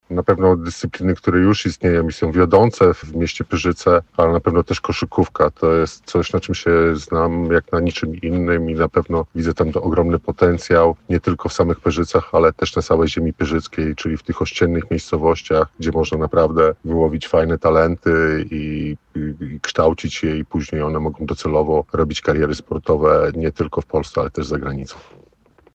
W rozmowie z Twoim radiem powiedział o swoich priorytetach związanych z rozwojem lokalnego sportu.